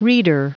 Prononciation du mot reader en anglais (fichier audio)
Prononciation du mot : reader